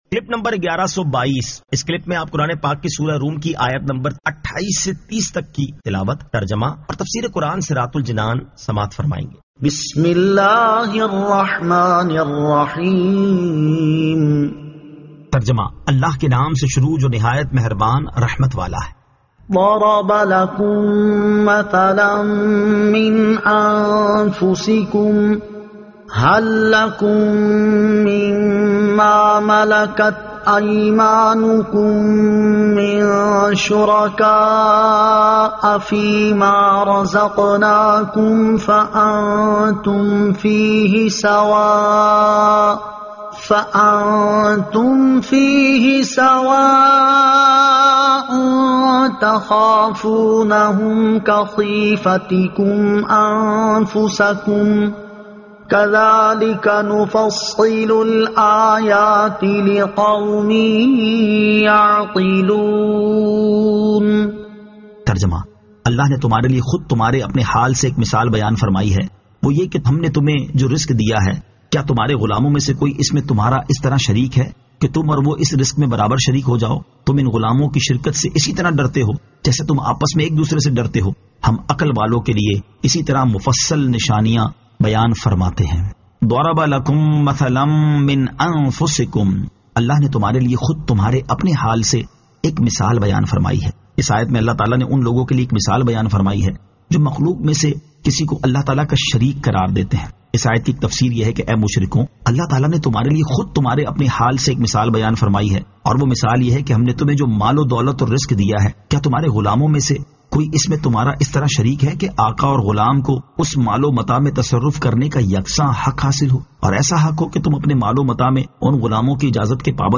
Surah Ar-Rum 28 To 30 Tilawat , Tarjama , Tafseer